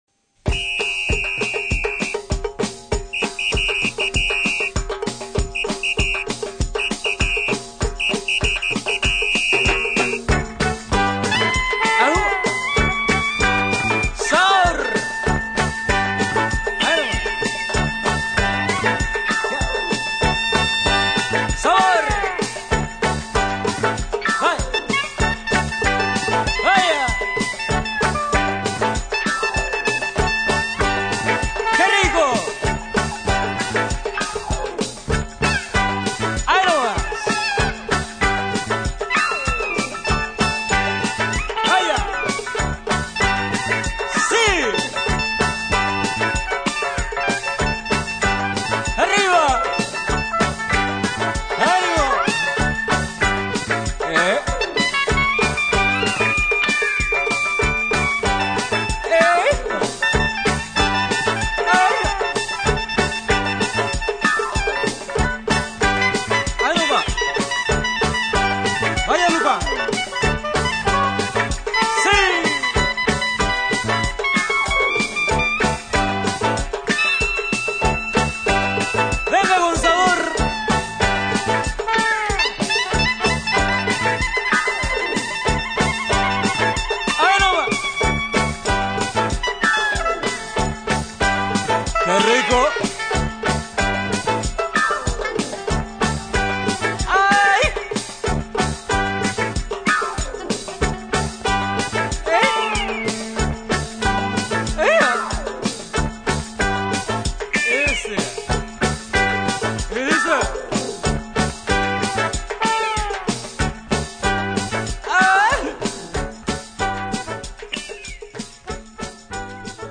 amerique du sud